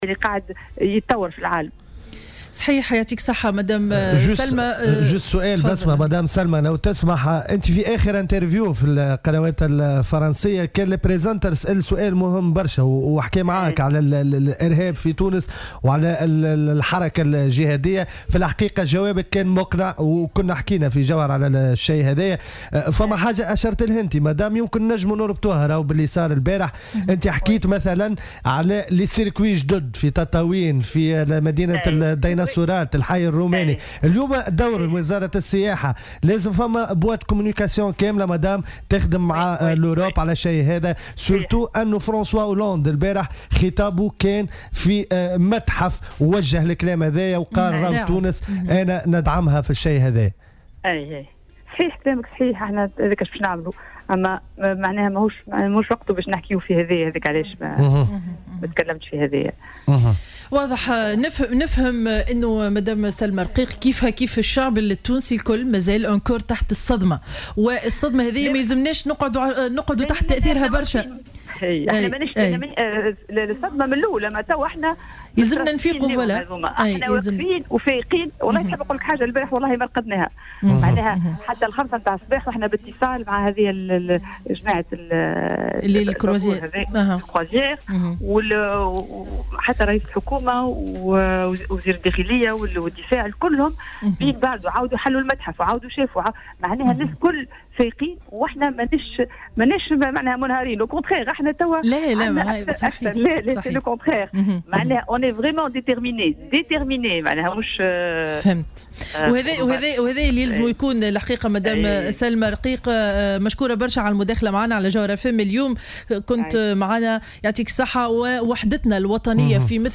أكدت وزيرة السياحة سلمى اللومي في مداخلة لها على جوهرة "اف ام" صباح اليوم الخميس أن تونس ليست متعودة بمثل هذه الكوارث وأن العنف ليس من عاداتها أو ثقافتها مشيرة إلى استغرابها من كيفية تجرؤ هؤلاء الإرهابيين على ضرب مكان مثل متحف باردو الذي يعد من أحسن المتاحف في العالم.